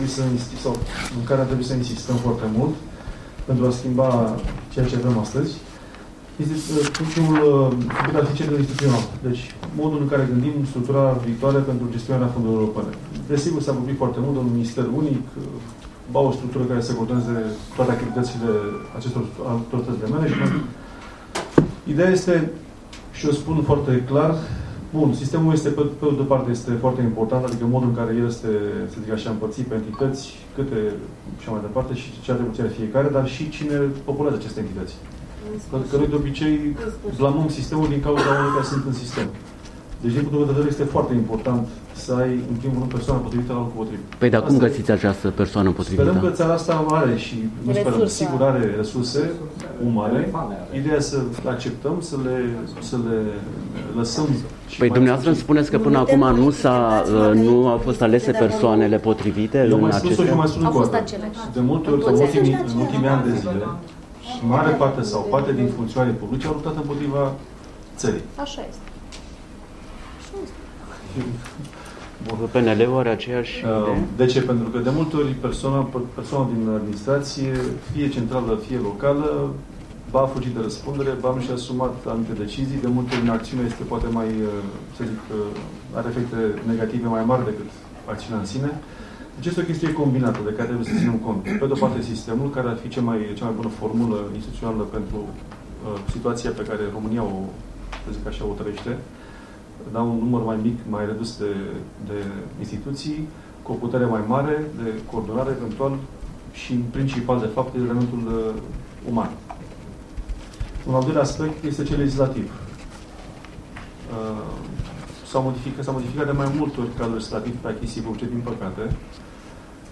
DEZBATERE SAR Ce propun partidele pentru cresterea absorbtiei fondurilor UE - România curată